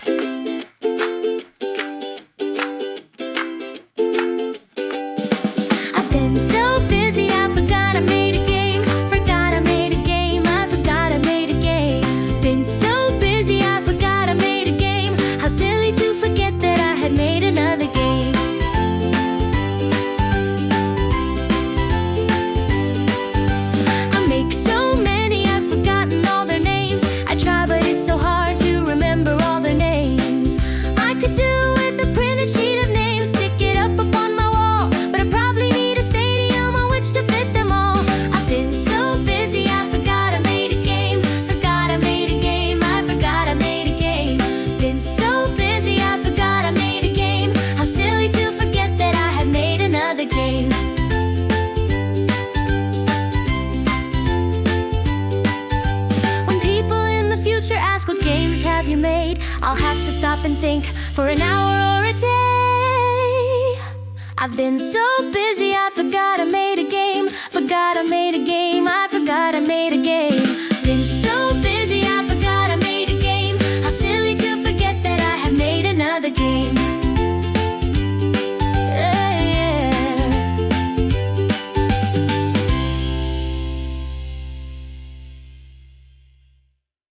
Sung by Suno
I_Forgot_(Cover)_mp3.mp3